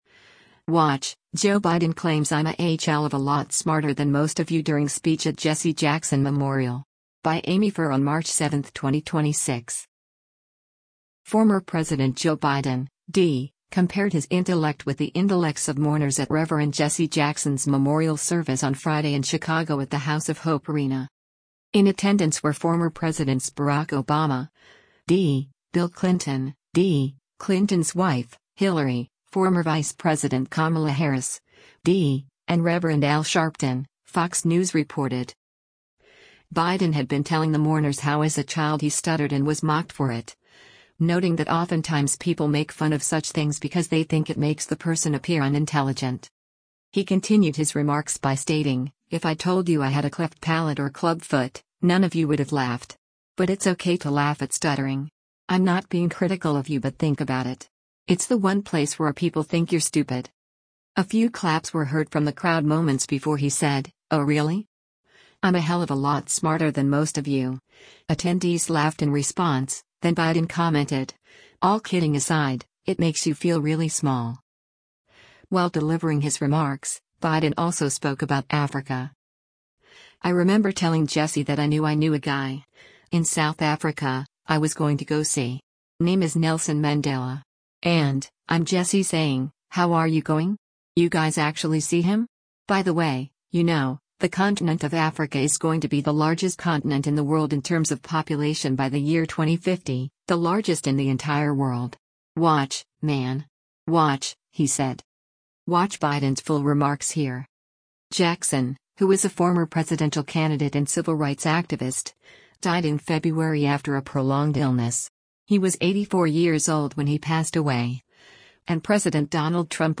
Joe Biden at Reverend Jesse L. Jackson's Peoples Celebration of Life and Homegoing Service
Former President Joe Biden (D) compared his intellect with the intellects of mourners at Reverend Jesse Jackson’s memorial service on Friday in Chicago at the House of Hope arena.
A few claps were heard from the crowd moments before he said, “Oh really? I’m a hell of a lot smarter than most of you.”